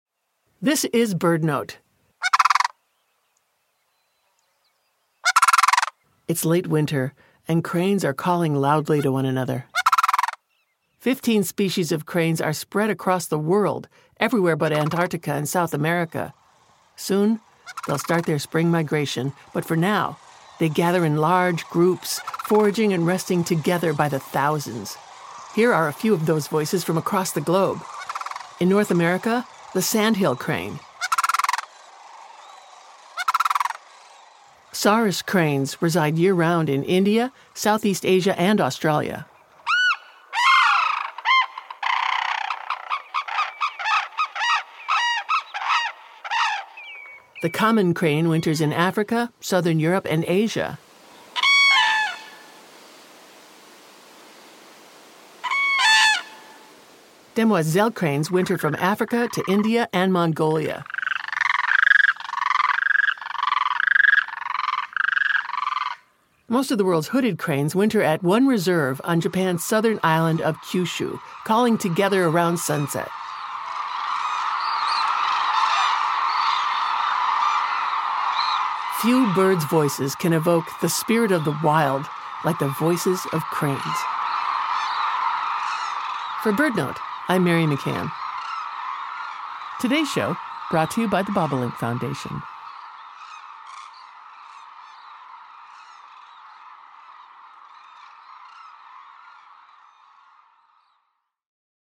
There are 15 species of cranes across the globe, found everywhere but Antarctica and South America. During the winter, cranes forage and rest together by the thousands. Listen in to the voices of cranes from all over the world.